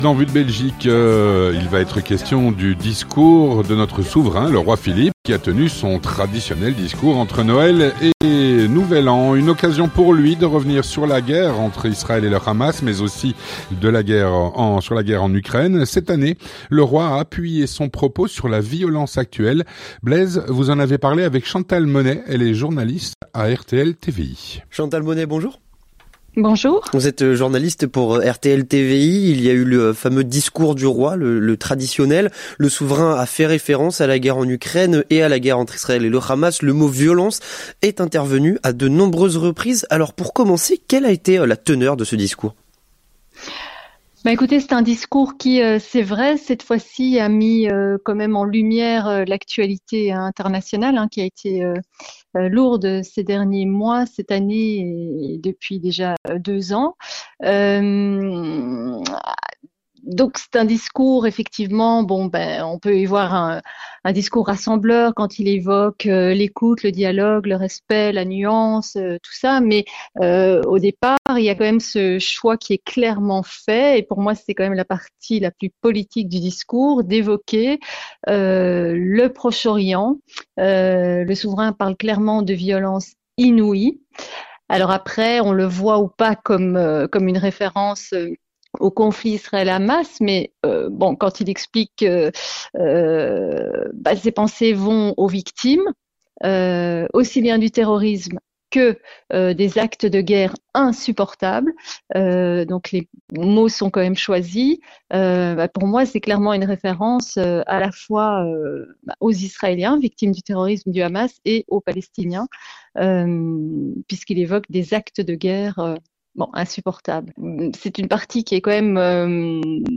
journaliste RTL-TVI
Présenté par